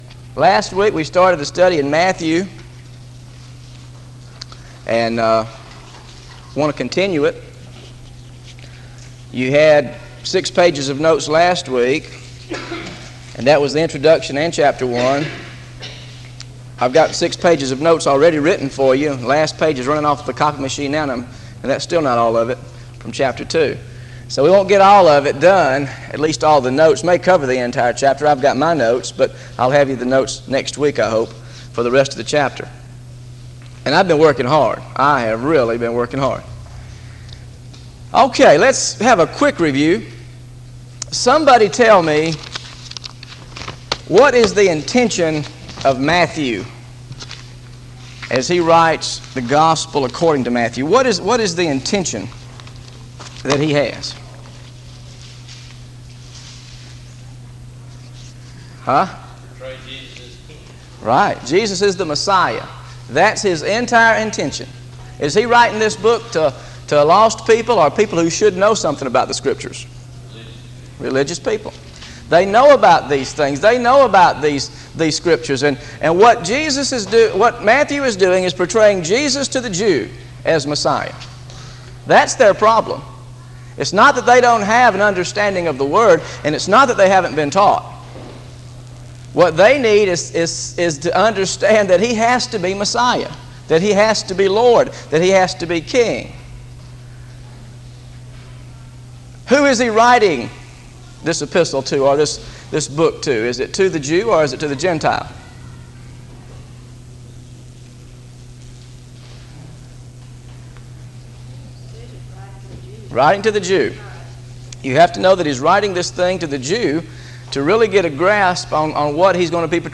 Audio Teaching – Matthew 2:1–11